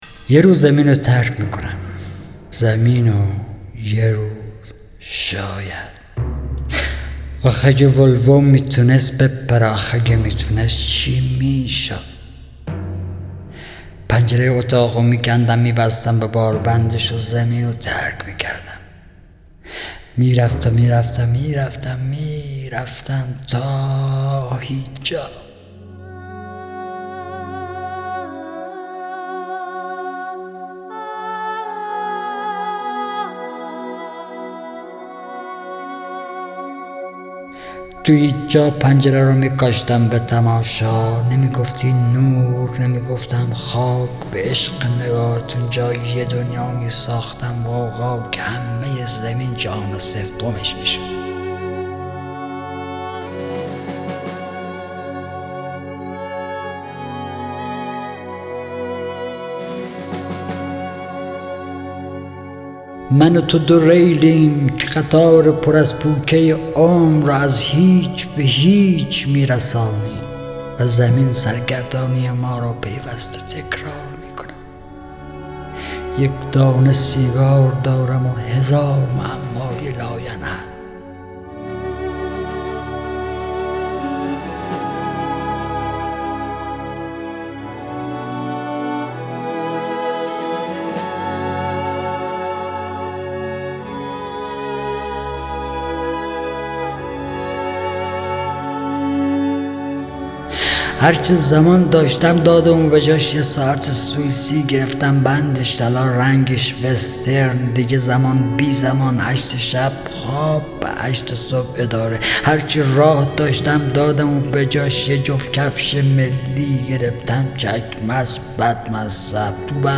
دانلود دکلمه افلاطون کنار بخاری با صدای حسین پناهی با متن دکلمه
گوینده :   [حسین پناهی]
آهنگساز :   بابک شهرکی